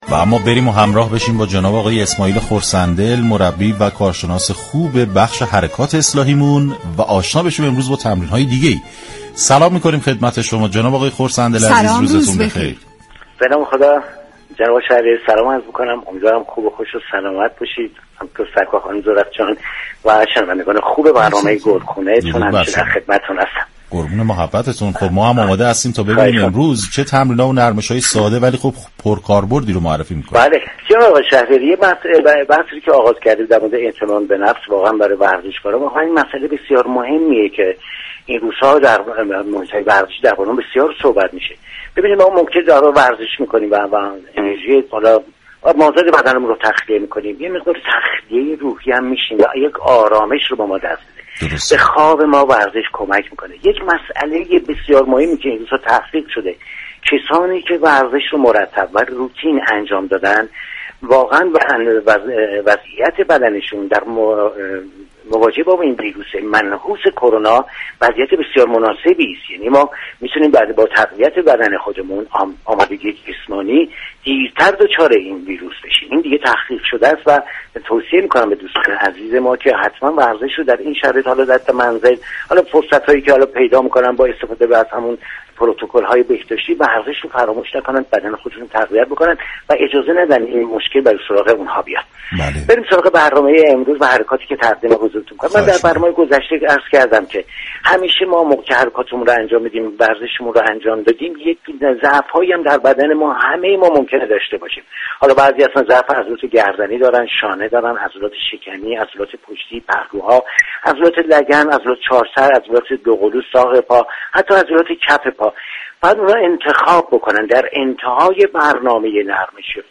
مربی و كارشناس حركات اصلاحی در گفتگو با رادیو ورزش به ارائه چند حركت ورزشی برای تقویت عضلات پایین تنه پرداخت.